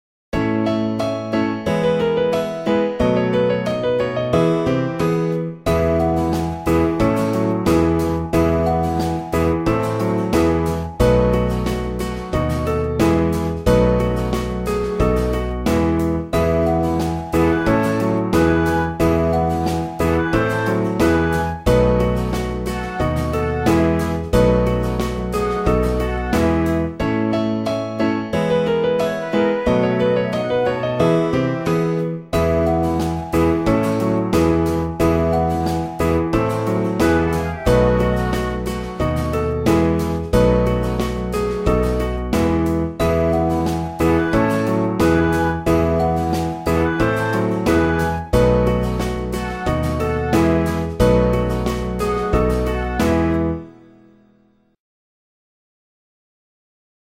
• Категория: Детские песни
караоке
минусовка